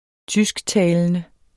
Udtale [ -ˌtæːlənə ]